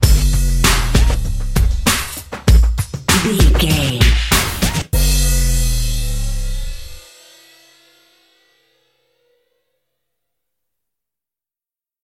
Ionian/Major
drum machine
synthesiser
funky